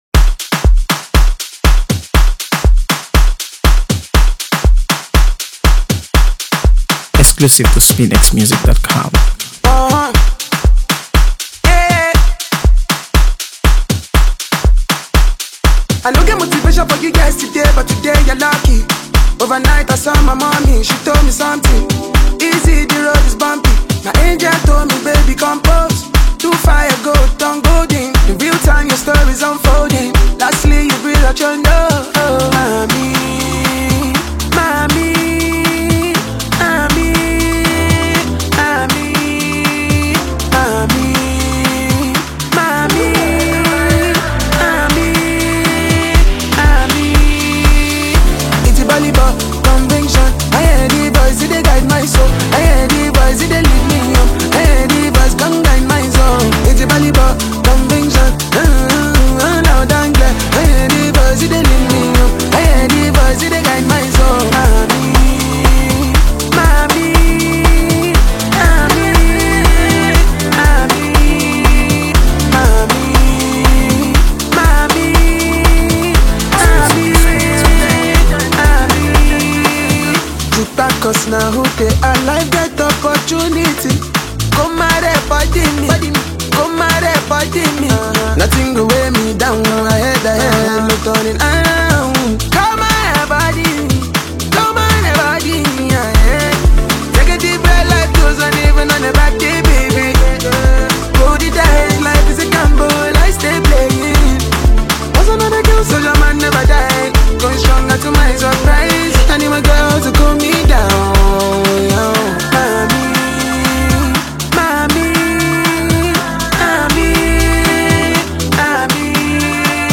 AfroBeats | AfroBeats songs
Nigerian singer and songwriter
Known for his smooth vocals and undeniable talent
With its catchy vibe and relatable message